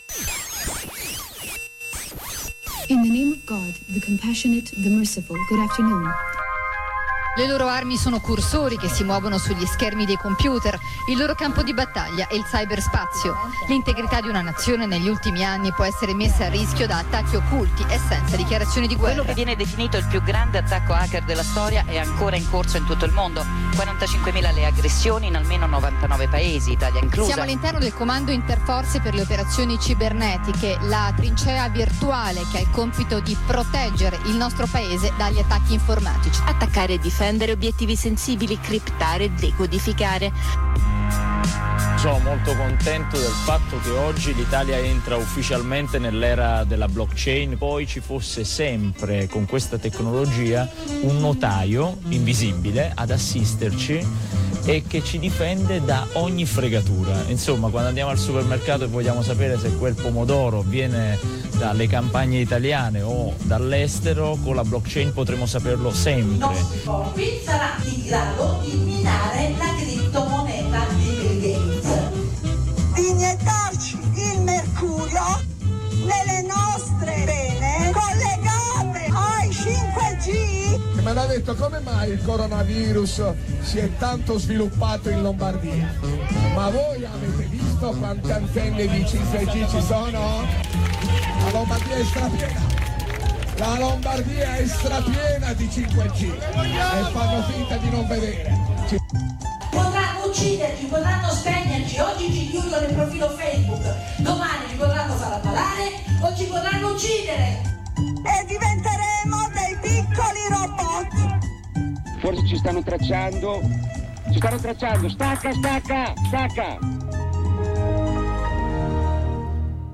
Intervistiamo